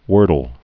(wûrdl)